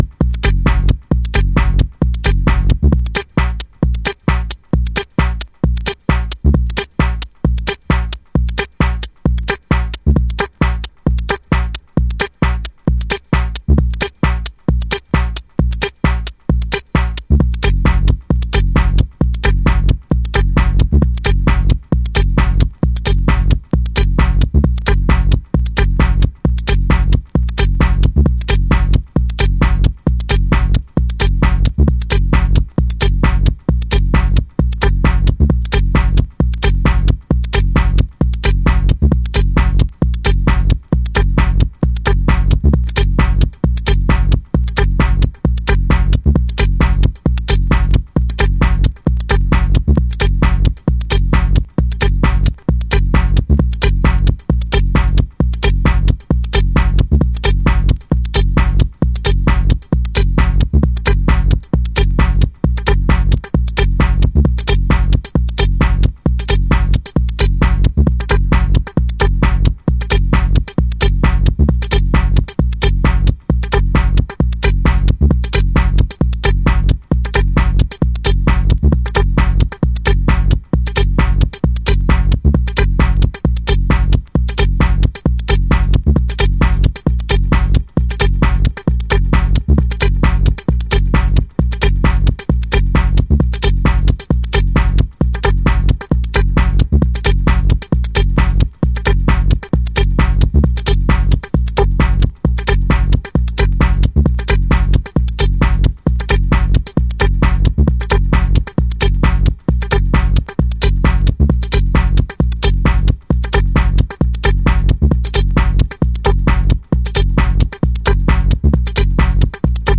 Early House / 90's Techno
装飾を一切省き骨組みだけで構成された彼岸の骨ミニマリズム